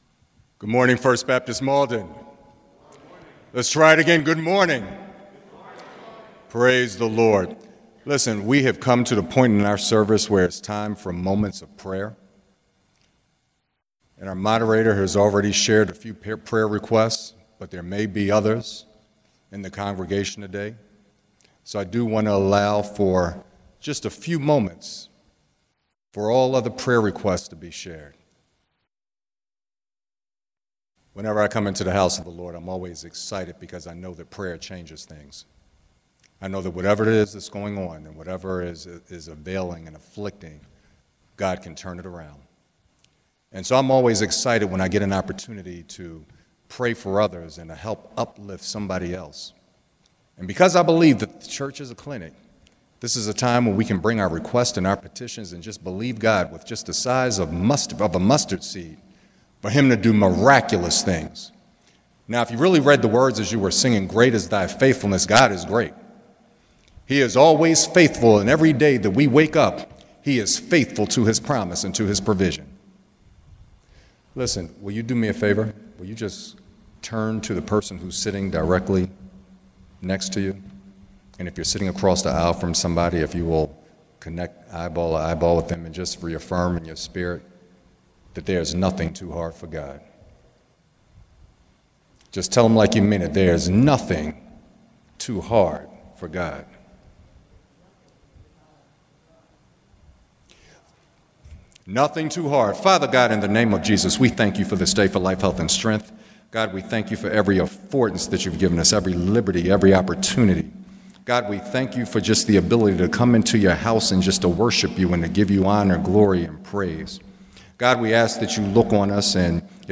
Labor Day Weekend Sunday Worship Service with Communion